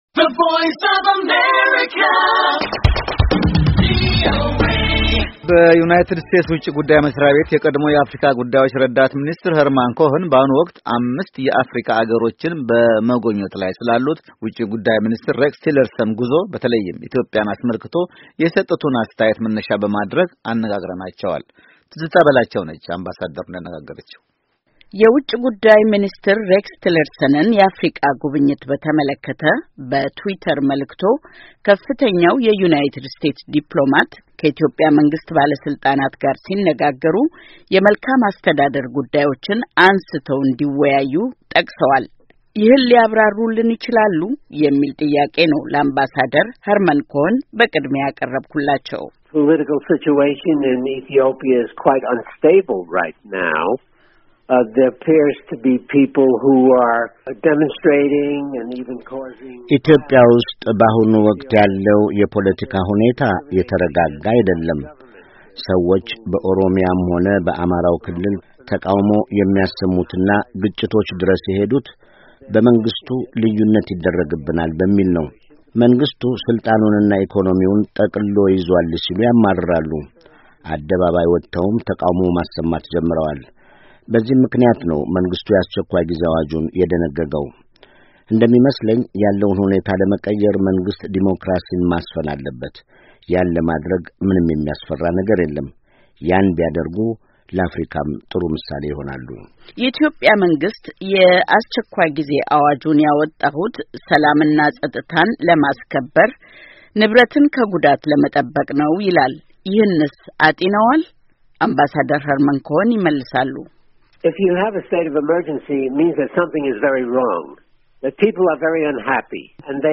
በዩናይትድ ስቴትስ ውጭ ጉዳይ መሥሪያ ቤት የቀድሞው የአፍሪካ ጉዳዮች ረዳት ሚኒስትር ሄርመን ኮኸን በአሁኑ ወቅት አምስት የአፍሪካ ሃገሮችን በመጉብኝት ላይ ስላሉት ውጭ ጉዳይ ሚኒስትር ሬክስ ቴለርሰን ጉዞ በተለይም ኢትዮጵያን አስመልክቶ የሰጡትን አስተያየት መነሻ በማድረግ አነጋግረናቸዋል።